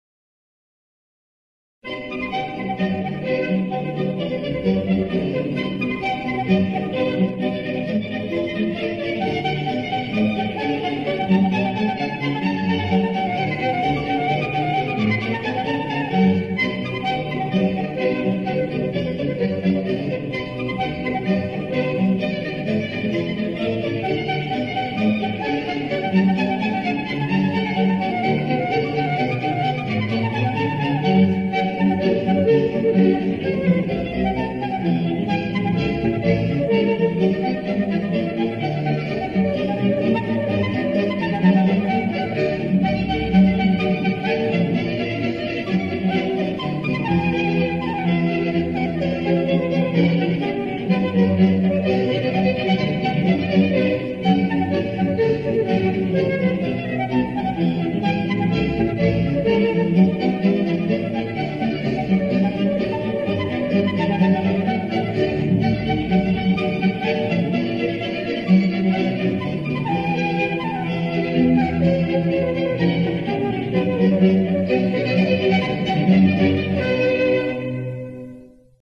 Слухання музики. Музична загадка
Зараз для вас прозвучать два твори у виконанні сопілки та флейти.
Сюїта для флейти (фрагмент)